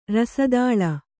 ♪ rasa dāḷa